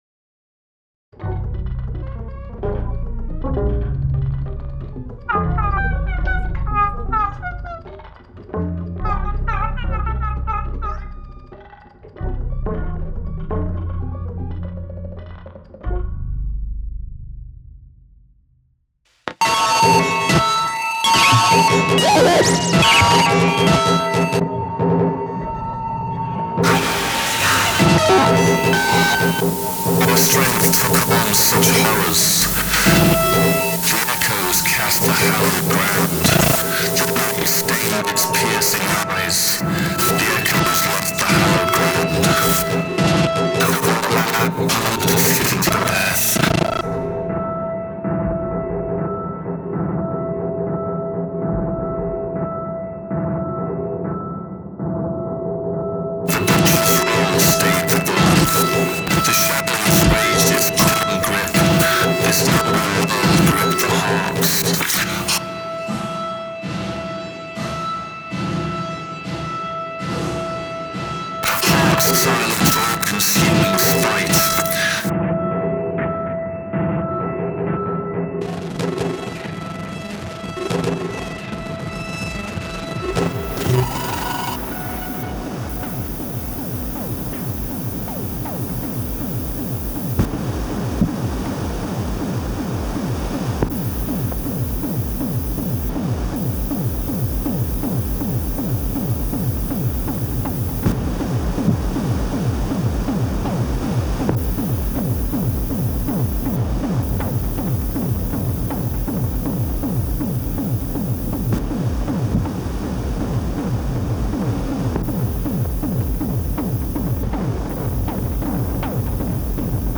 To make a fair comparison, I’ve made several different renders of a section of my current composition, composed in 5th-order ambisonics.
It also has sounds from all around and above the listener (but generally not below).
lusted-fleeting-demo8-uhj.wav